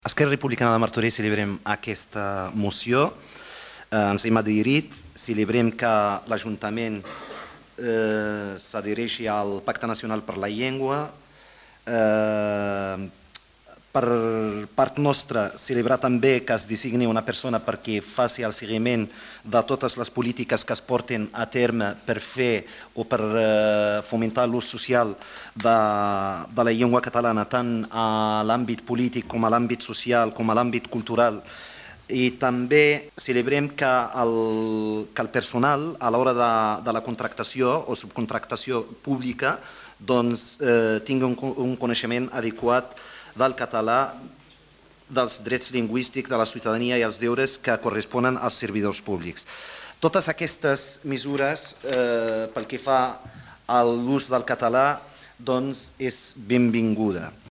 Soulimane Messaoudi, regidor d'ERC Martorell